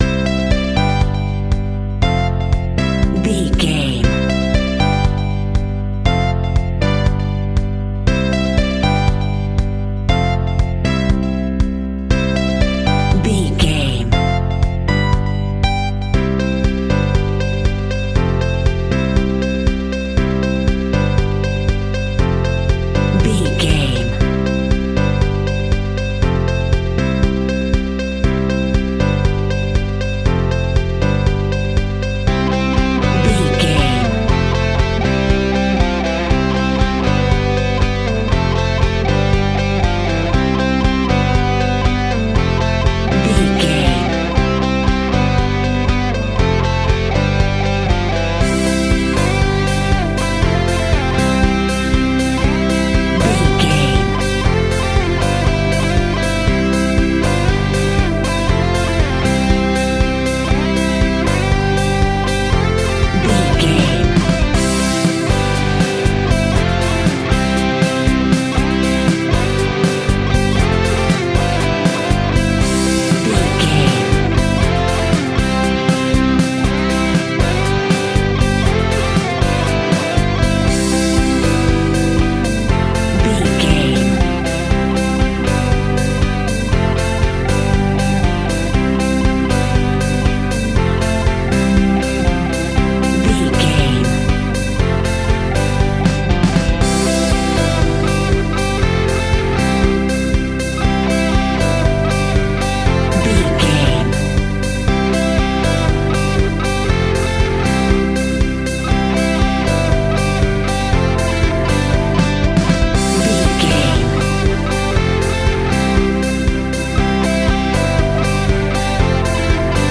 Ionian/Major
pop rock
fun
energetic
uplifting
instrumentals
indie pop rock music
upbeat
groovy
guitars
bass
drums
piano
organ